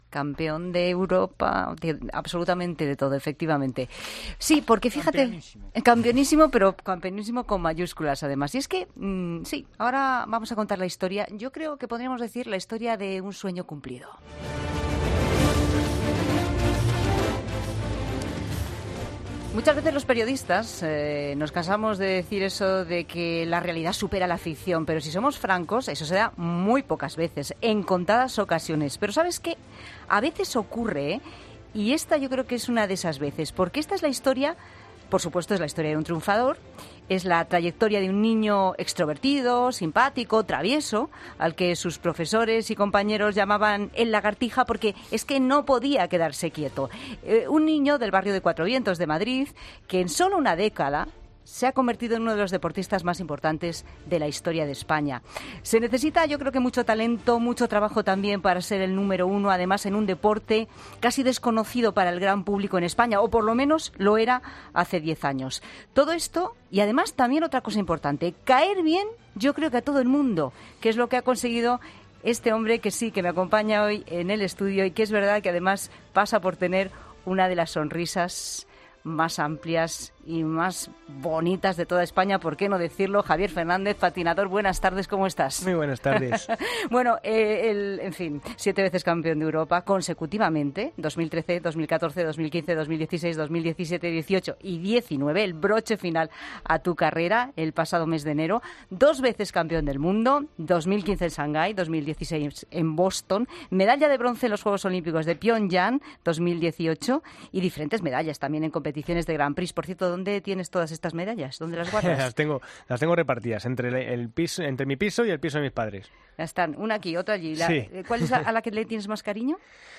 El patinador Español ha estado en 'La Tarde de COPE' para explicar los motivos de su retirada y también lo importante que es el patinaje para él.